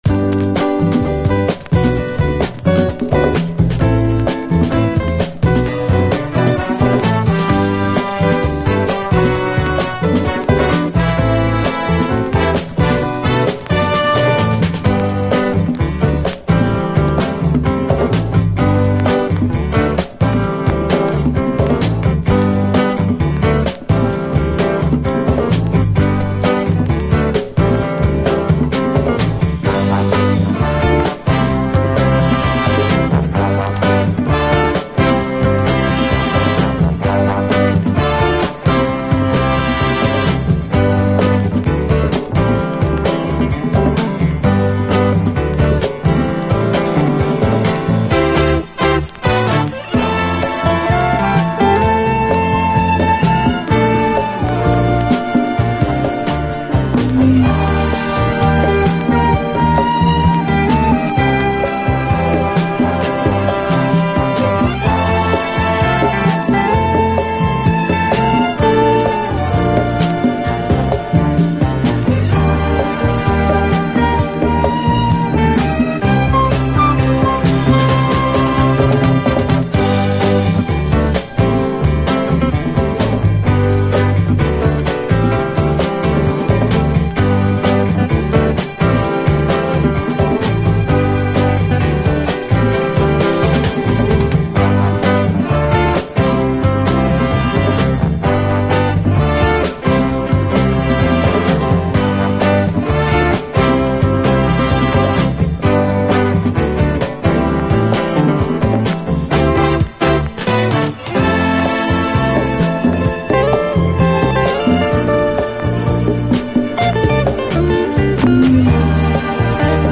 異色のアフロ・ニューウェーヴ・ファンク〜アフロ・エレクトロ！
攻撃的なビートとエディットも挟みこむ異端センスのアフロ・ニューウェーヴ・ディスコ